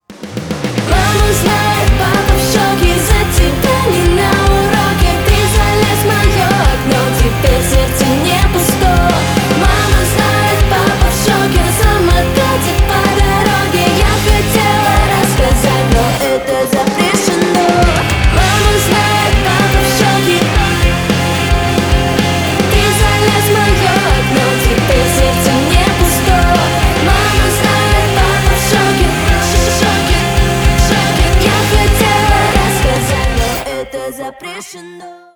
Поп Музыка
громкие # клубные